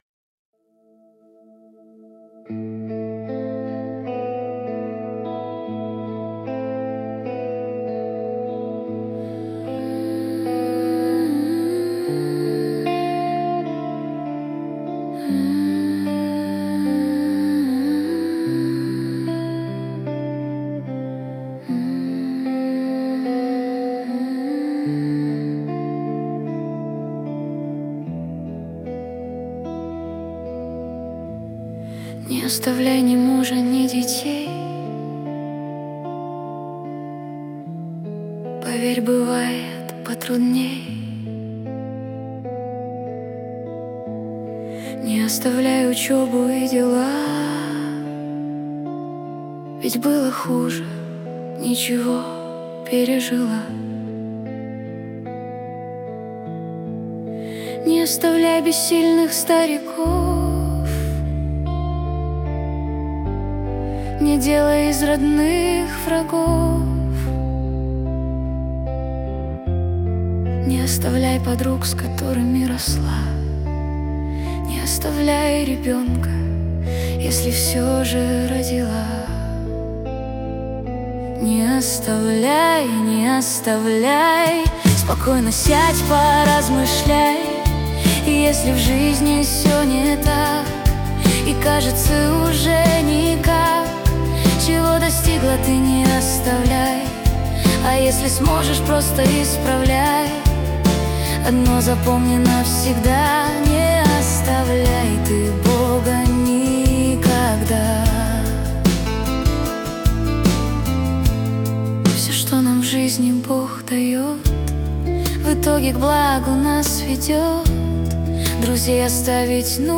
236 просмотров 786 прослушиваний 77 скачиваний BPM: 75